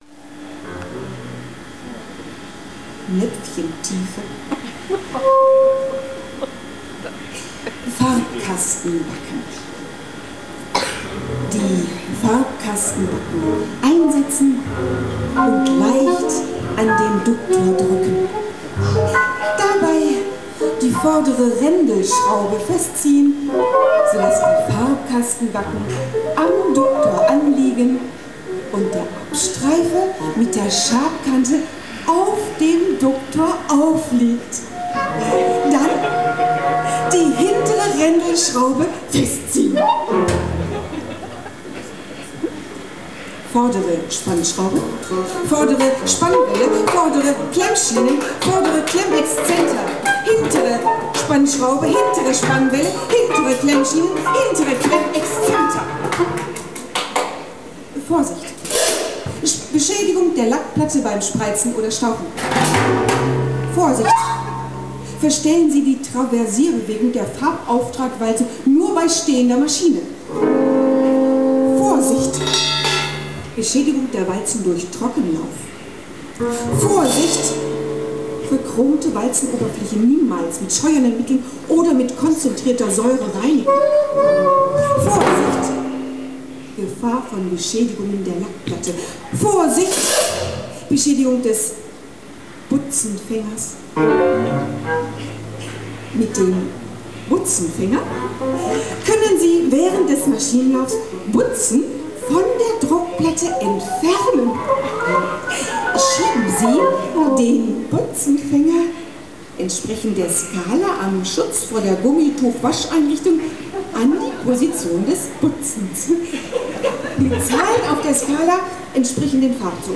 Performance anläßlich der Einweihung von Heidelberger Druckmaschinen am 9.5.03 bei der Dortmunder Druckerei Hitzegrad